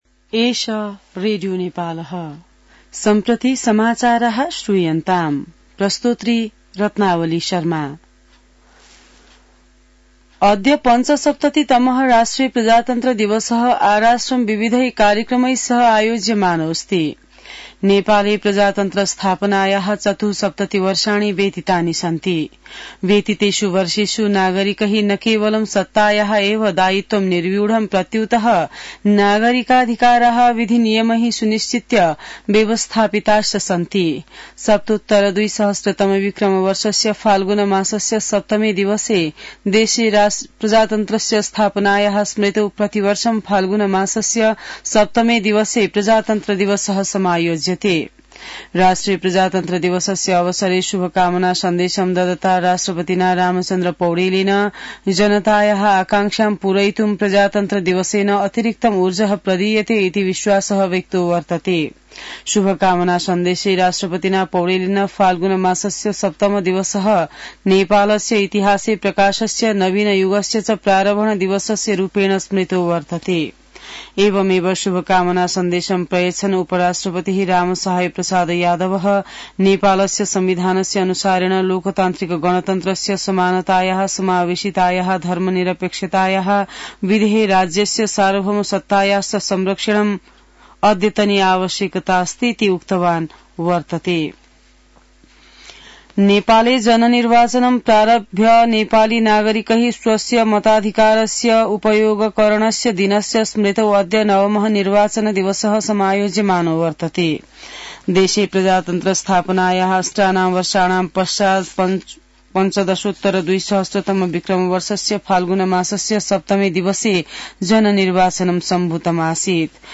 संस्कृत समाचार : ८ फागुन , २०८१